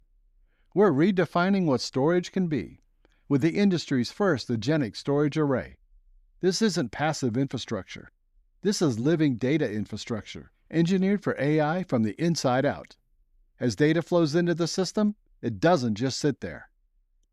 Male
Adult (30-50), Older Sound (50+)
I have a Deep, Friendly, Relatable, Professional voice that can complement almost any project and make it shine!
Radio Commercials
Ad Announcer